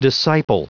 Prononciation du mot disciple en anglais (fichier audio)
Prononciation du mot : disciple